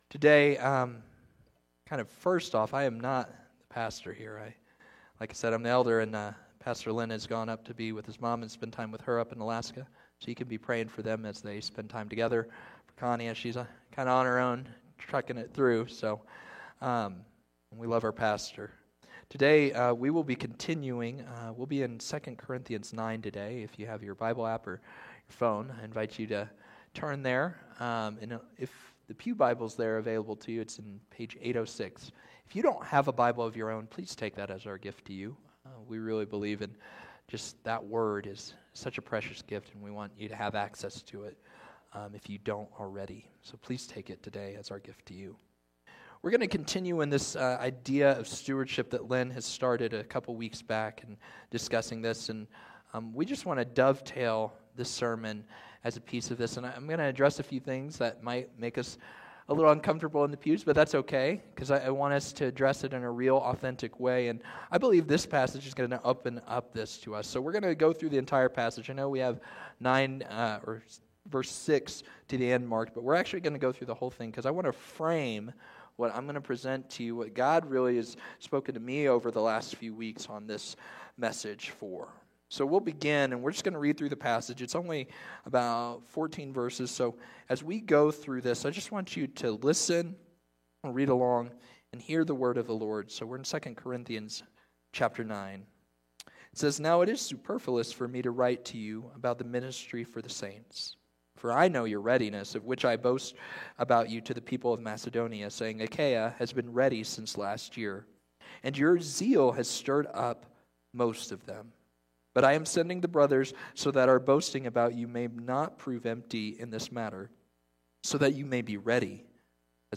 Sermons | First Baptist Church of Golden
Guest Speaker